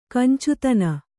♪ kancutana